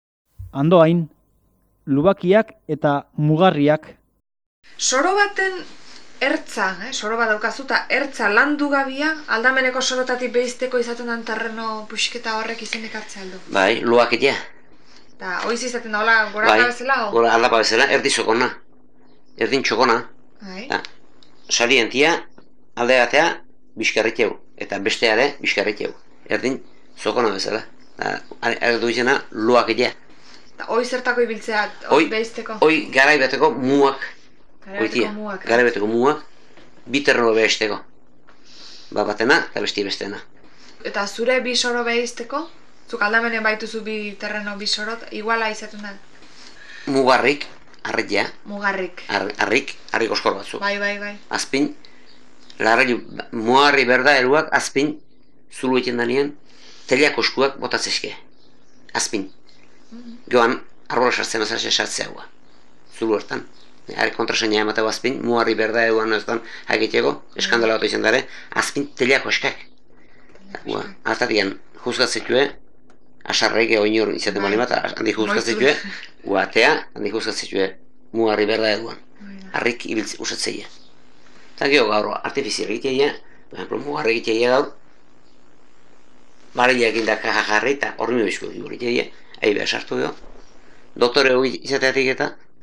Andoain.mp3